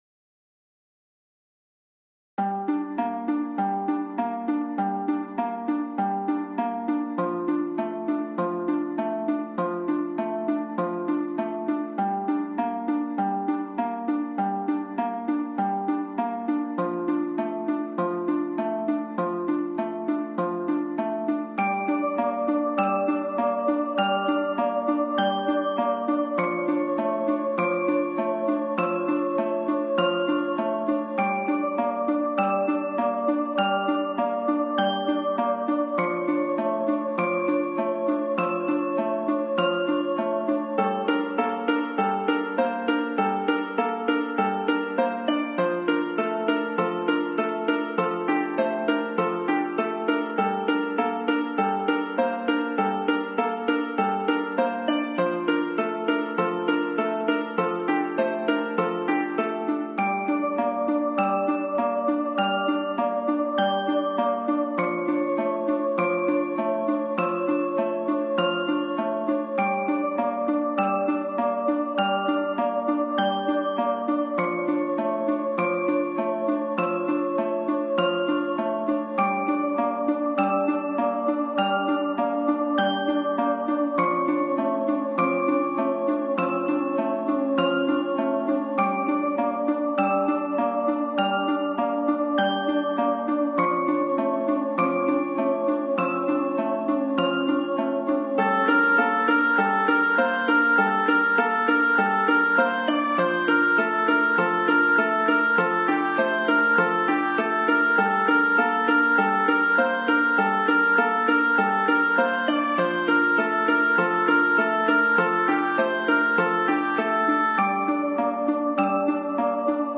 - Ambient
The link above are in low quality, if you want a better sound, you must download the FLAC ones from OGA.